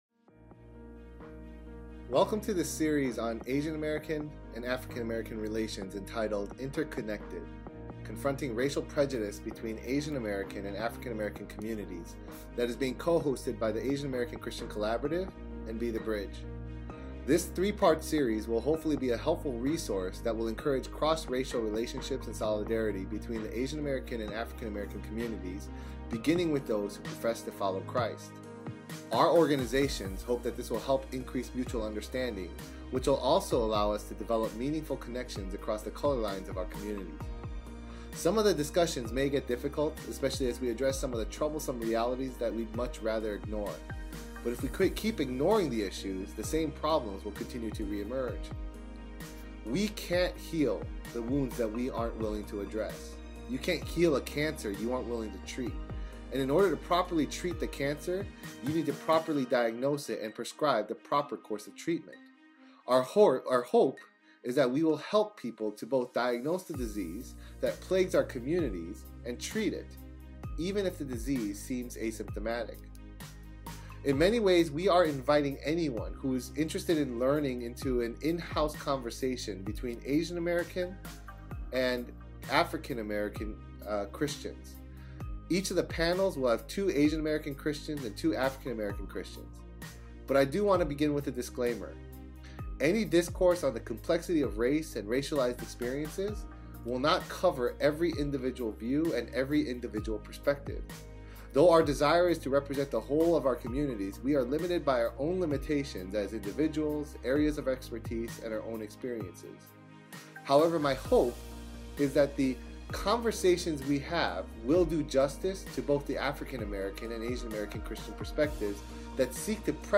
Genre Discussion Panel